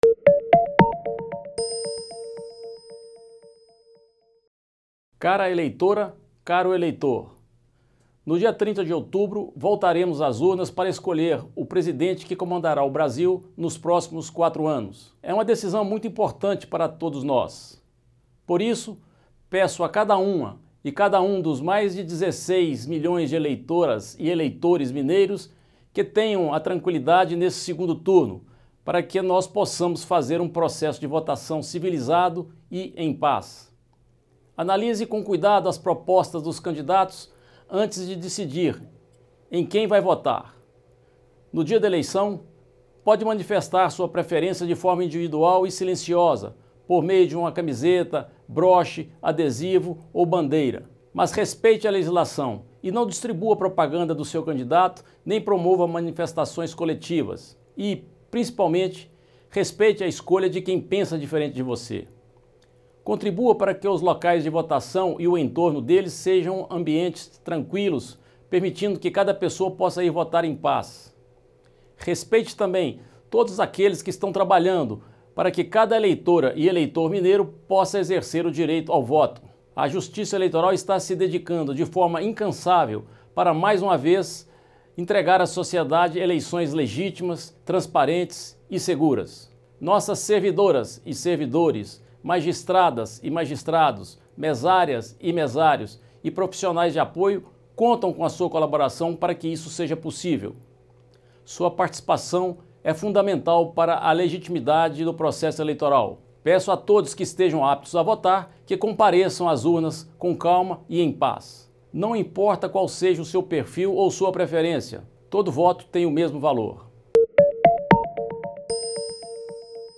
Discursos e Palestras
Em pronunciamento, o desembargador Maurício Soares, do Tribunal Regional Eleitoral em Minas, explica o que pode ser feito por eleitores e candidatos neste domingo (30/10), 2º turno das Eleições 2022.